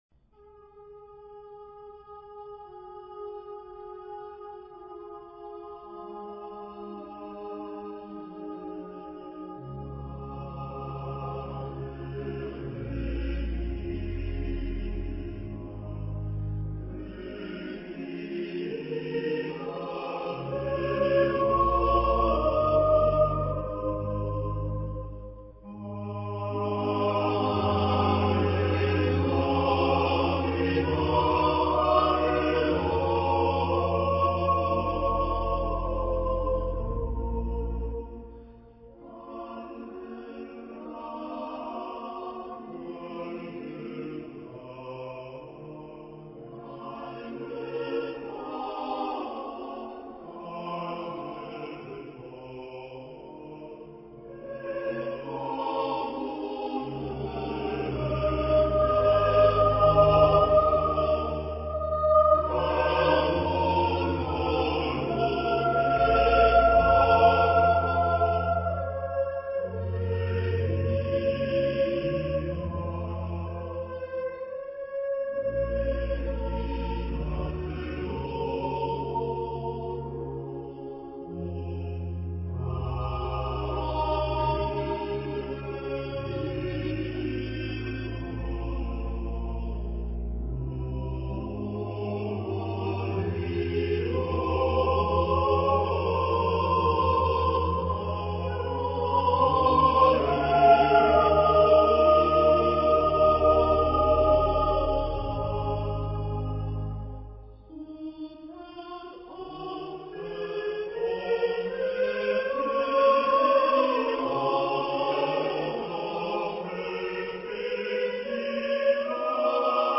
Genre-Style-Forme : Romantique ; Sacré ; Motet ; Antienne
Type de choeur : SATB  (4 voix mixtes )
Instruments : Orgue (1)
Tonalité : ré bémol majeur